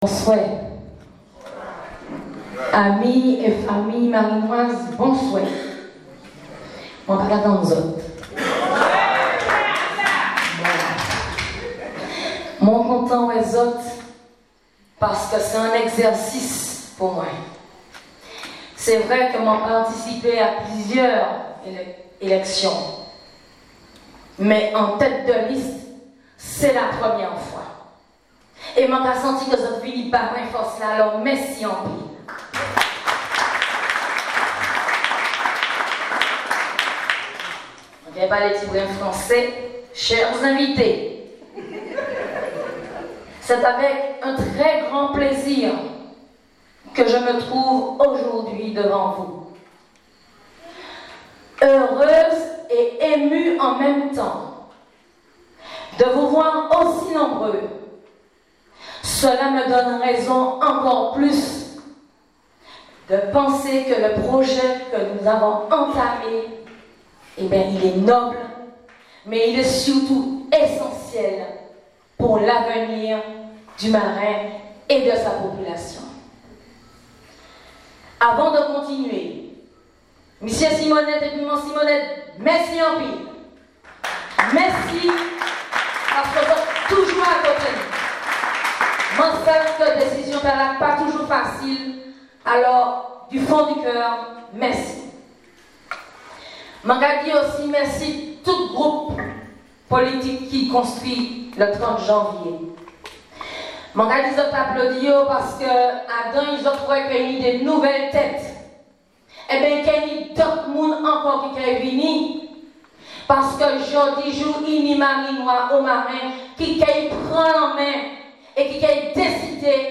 Marin... Michelle Bonnaire, fait salle comble, pour le lancement de sa campagne électorale.